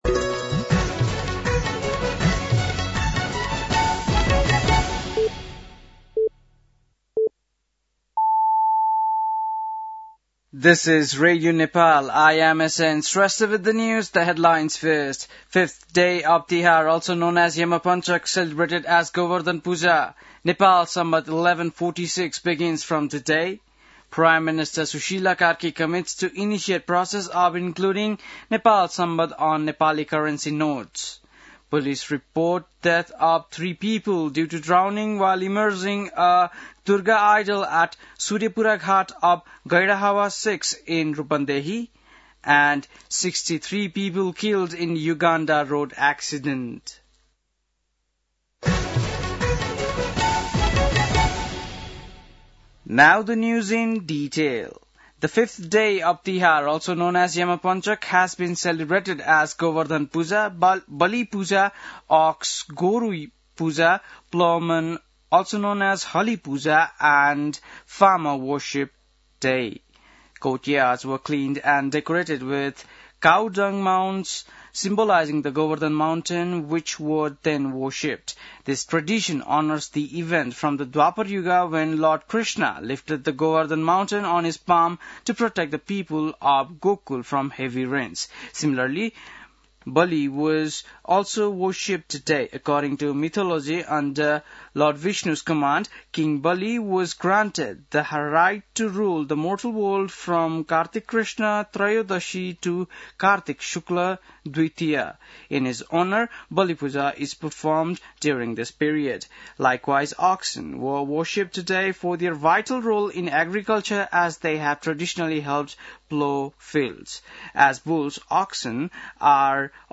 बेलुकी ८ बजेको अङ्ग्रेजी समाचार : ५ कार्तिक , २०८२